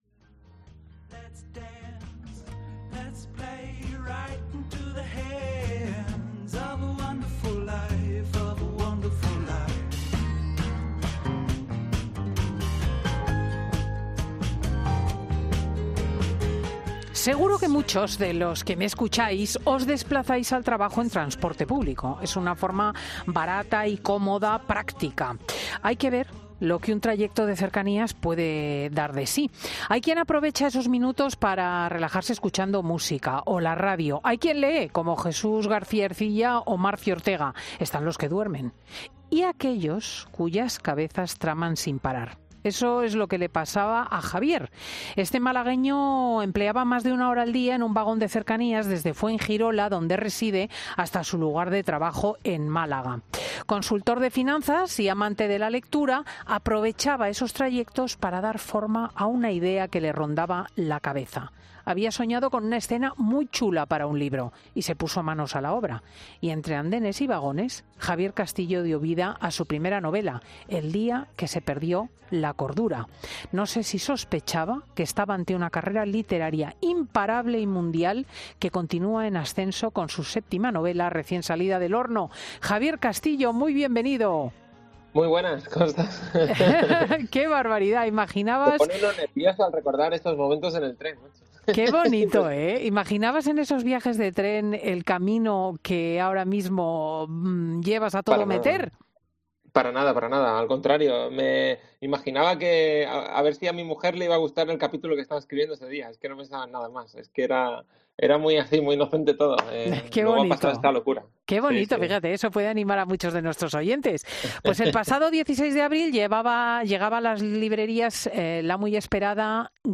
Y presenta en Fin de Semana su última novela: 'La grieta del...